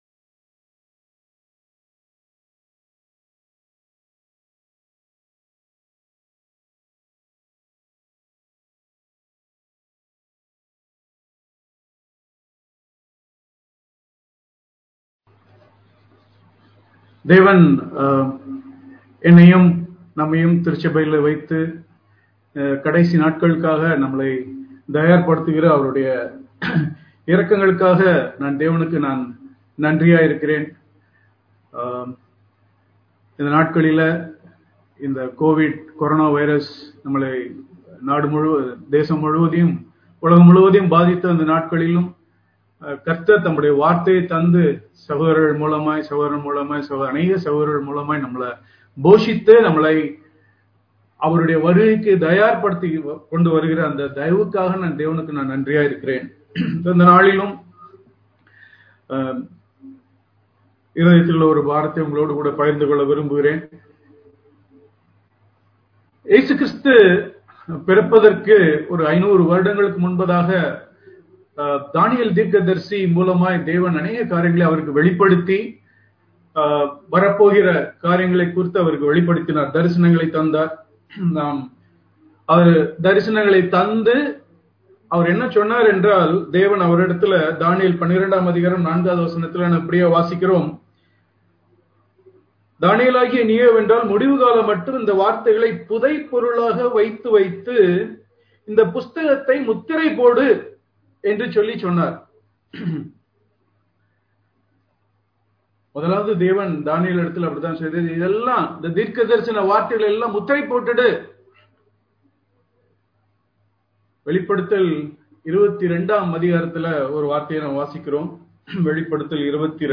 Thoothukudi Conference - 2021
Sermons